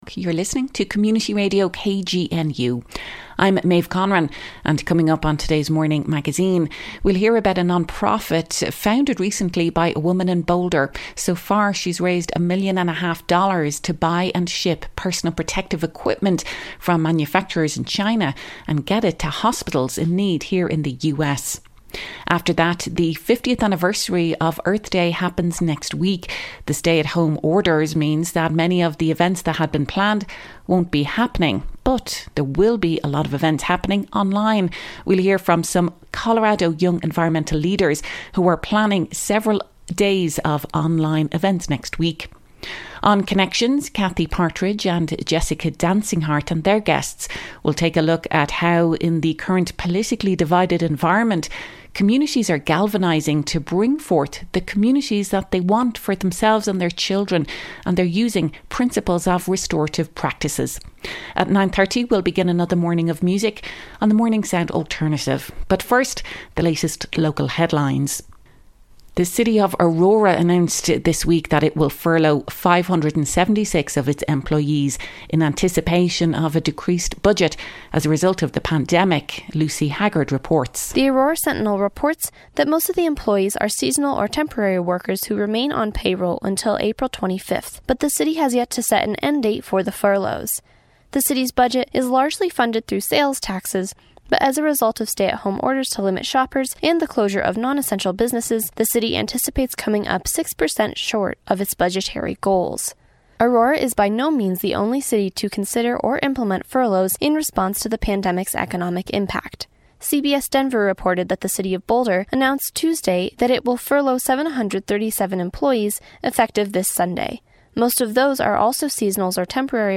A Boulder woman tells us about the non-profit she started to buy and ship personal protective equipment (PPE) to U.S. hospitals in need. Then, colorado environmental leaders speak to KGNU in advance of several days of online Earth Day inspired events scheduled for next week.